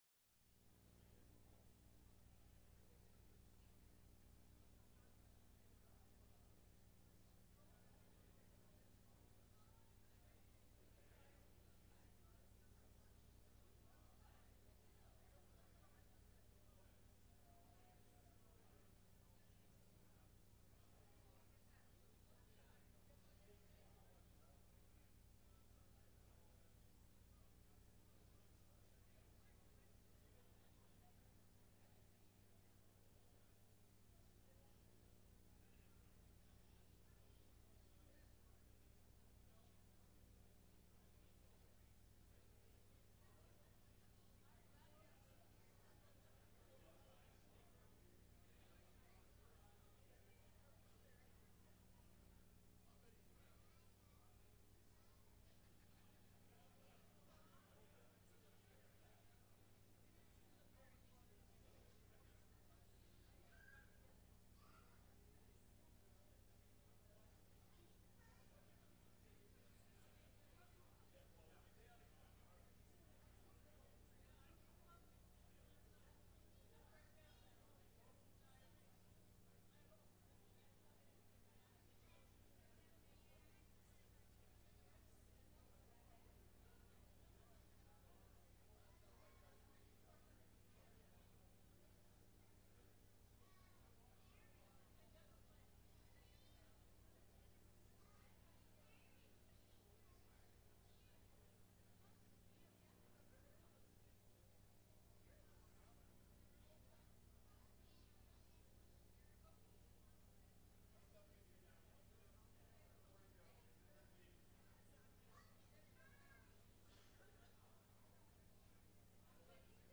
5_8-22-Sermon.mp3